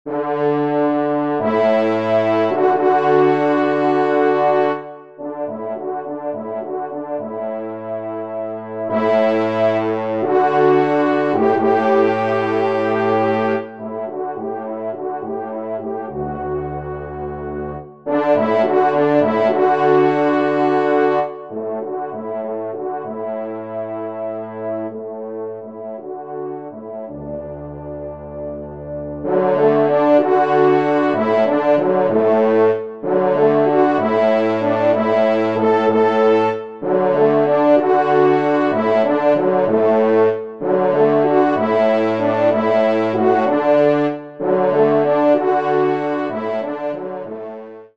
5e Trompe